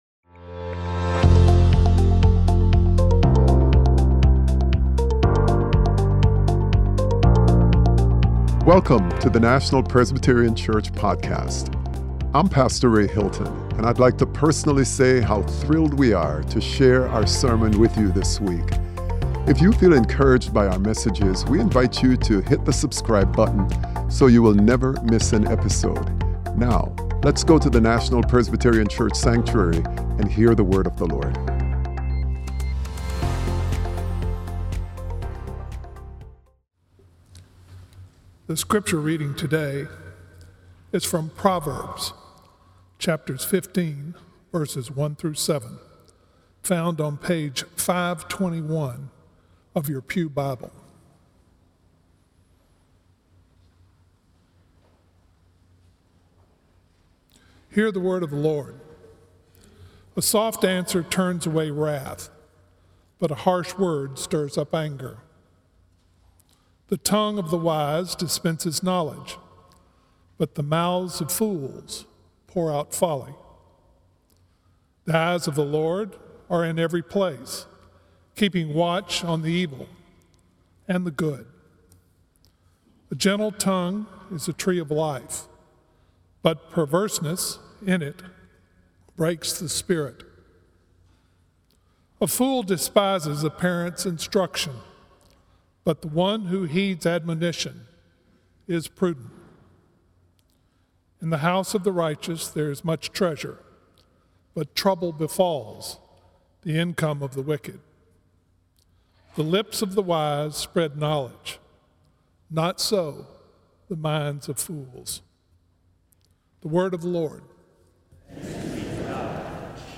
Sermon: Flourishing Together - Building a Relationship with Kind Words - National Presbyterian Church